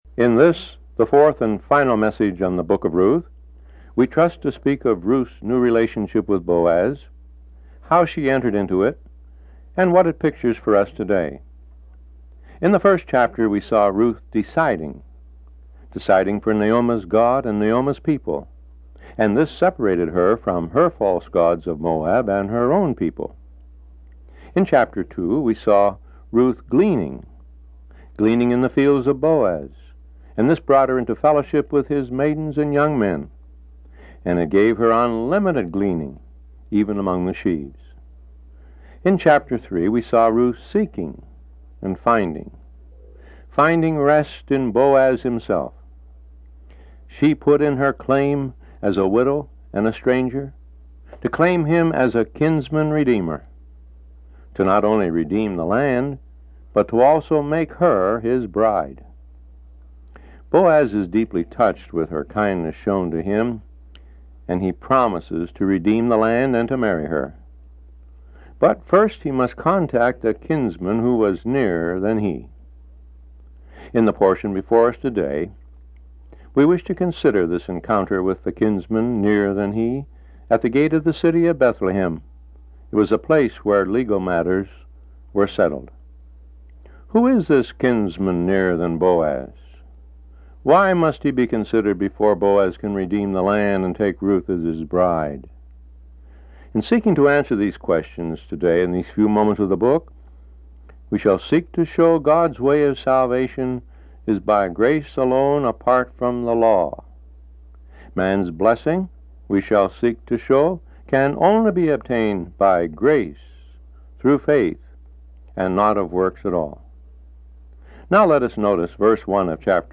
This is the last in a series of four expository messages on the book of Ruth. This message covers Ruth chapter 4, and contrasts the nearer kinsman (the law) with Boaz (grace).